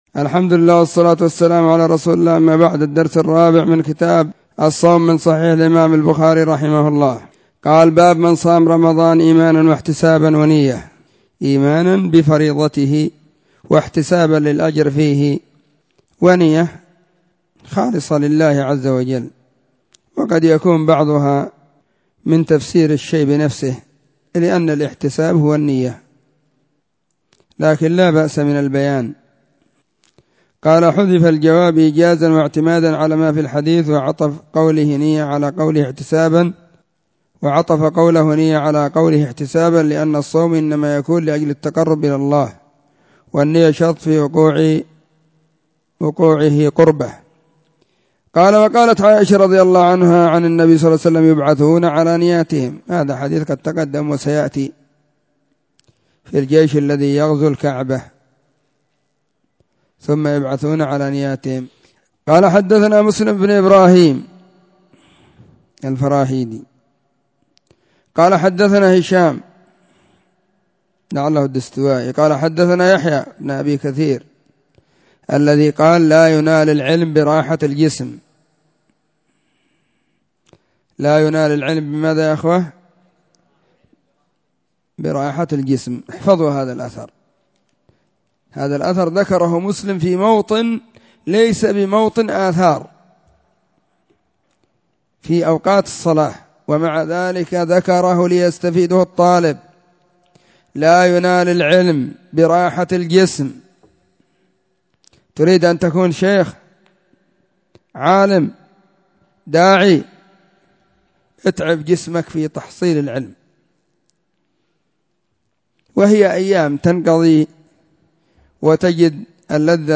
🕐 [بين مغرب وعشاء – الدرس الثاني]